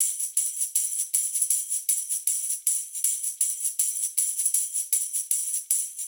Index of /musicradar/sampled-funk-soul-samples/79bpm/Beats
SSF_TambProc2_79-02.wav